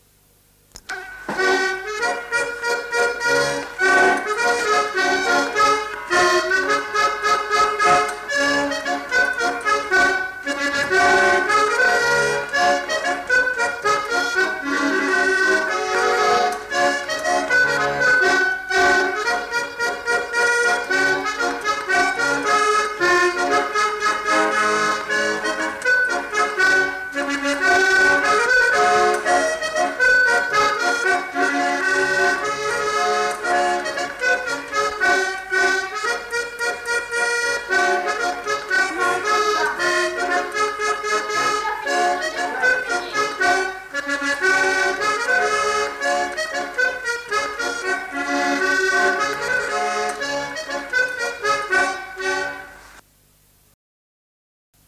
Lieu : Mas-Cabardès
Genre : morceau instrumental
Instrument de musique : accordéon diatonique
Notes consultables : Le joueur d'accordéon n'est pas identifié. Il joue sans doute une figure de quadrille.